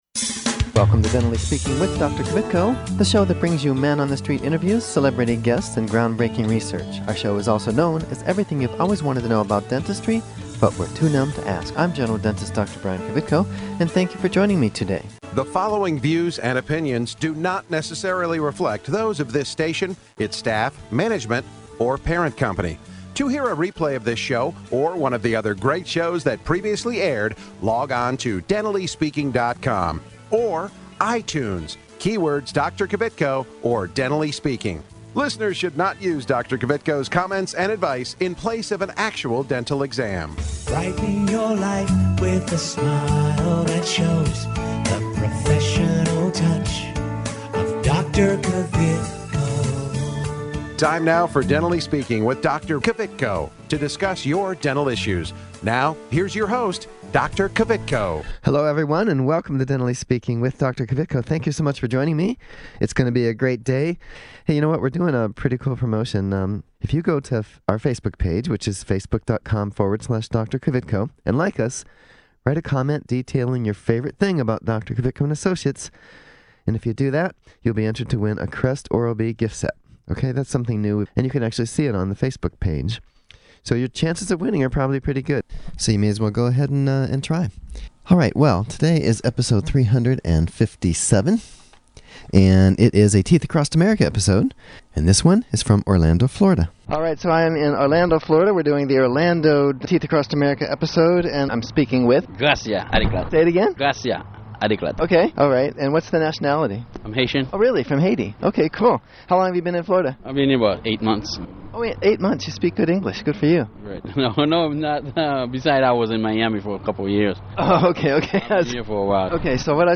Teeth Across America: Interviews from Orlando, FL”